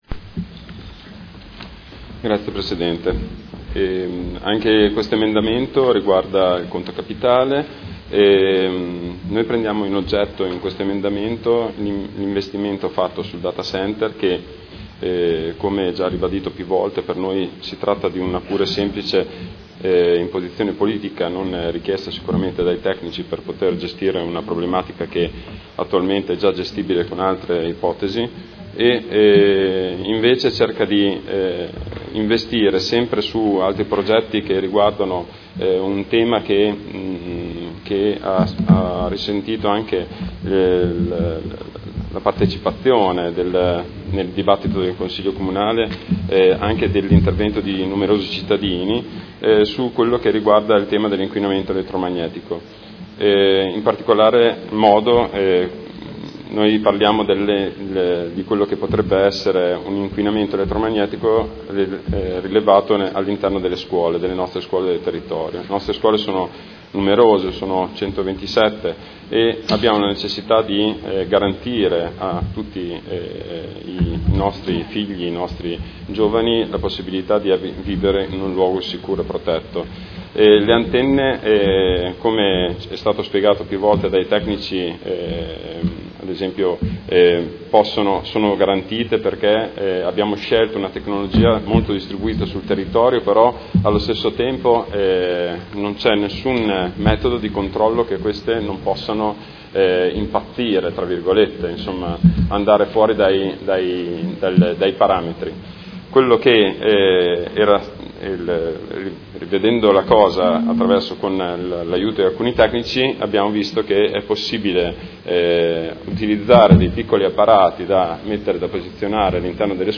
Seduta del 26 gennaio. Bilancio preventivo: emendamento n°9689